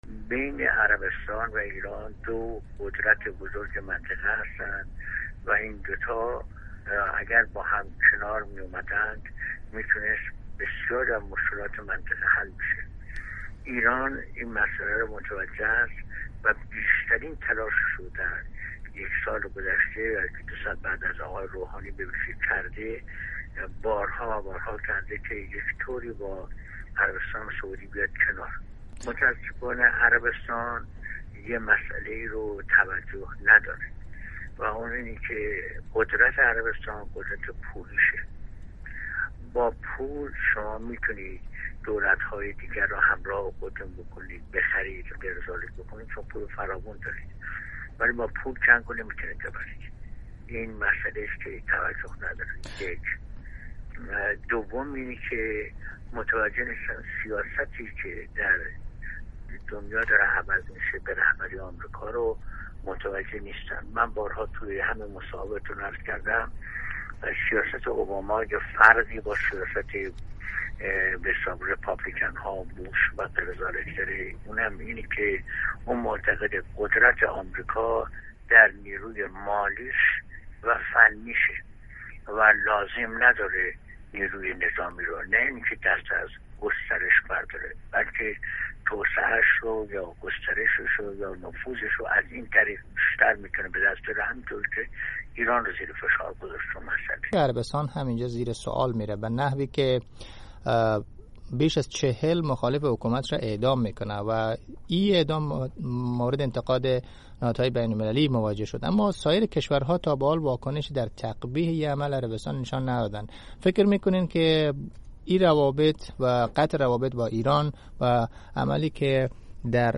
مصاحبه